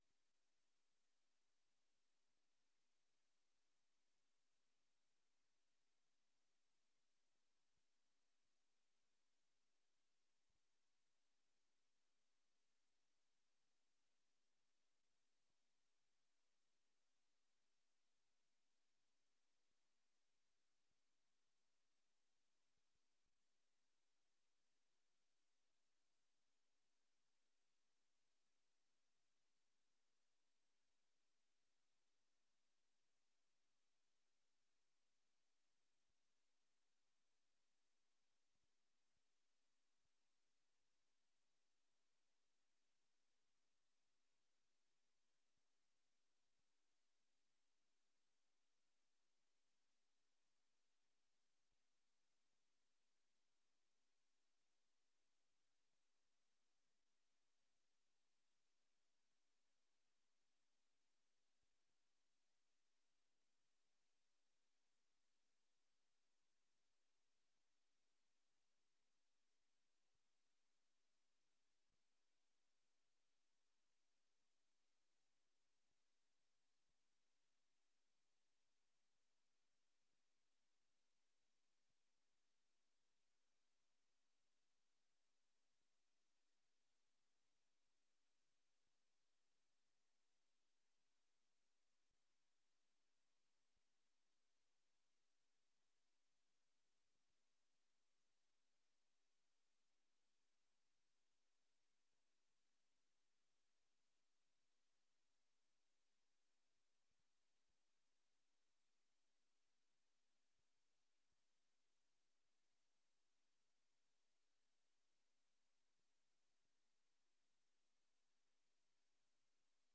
Oordeelsvormende vergadering 23 mei 2024 19:30:00, Gemeente Dronten
Locatie: Raadzaal Voorzitter: Peter Bentum